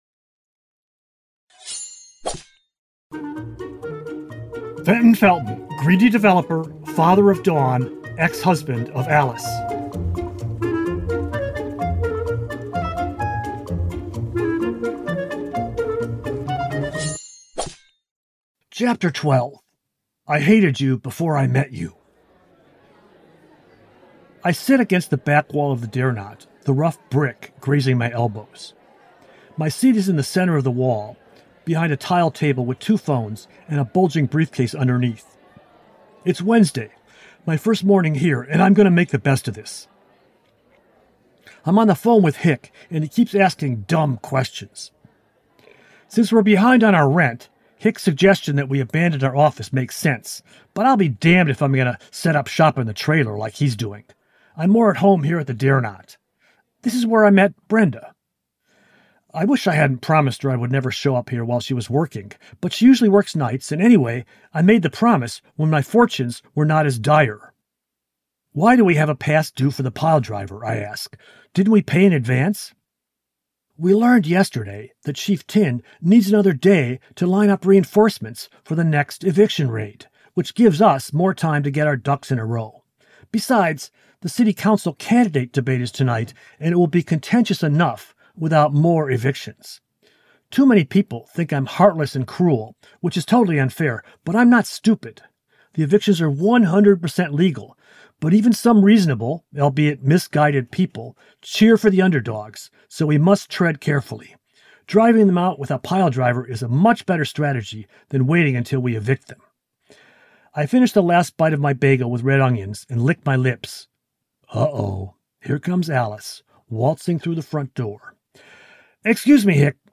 I used a gravelly and bombastic voice for him.
Each chapter starts with one of these musical stings.